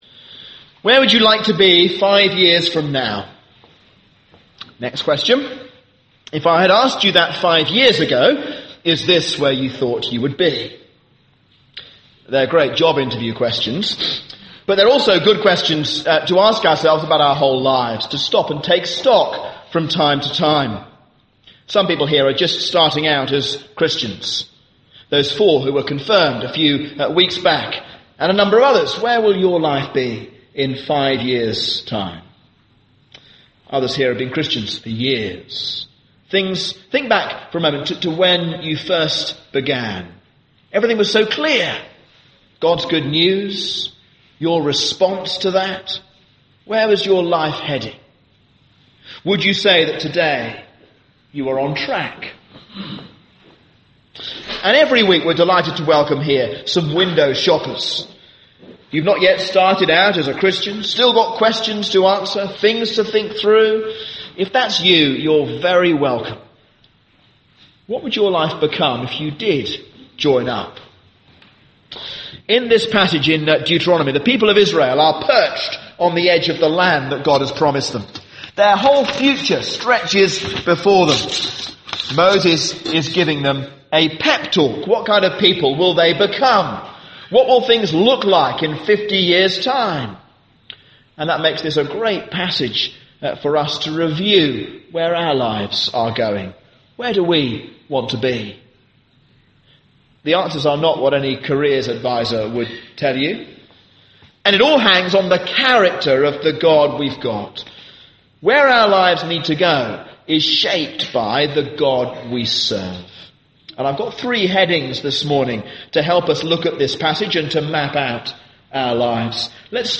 A sermon on Deuteronomy 6:1-15